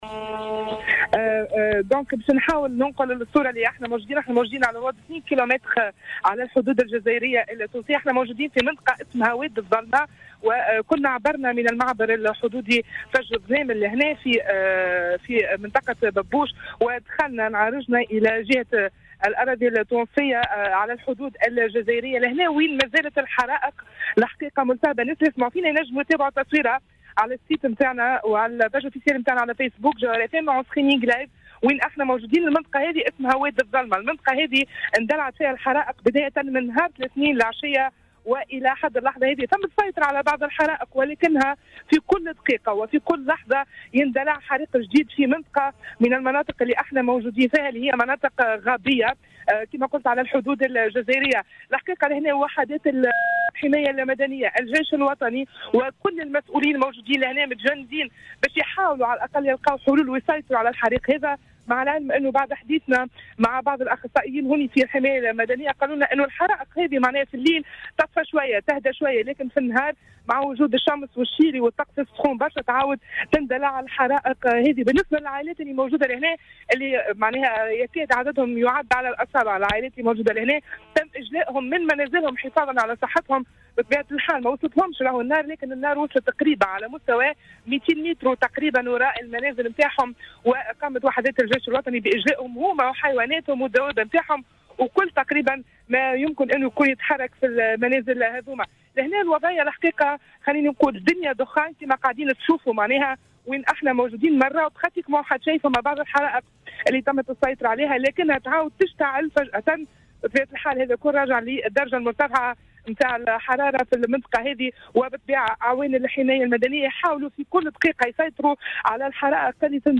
تحول فريق "برنامج" بوليتيكا" اليوم إلى ولاية جندوبة وذلك لمتابعة مستجدات الأوضاع إثر سلسلة الحرائق التي تشهدتها الجهة.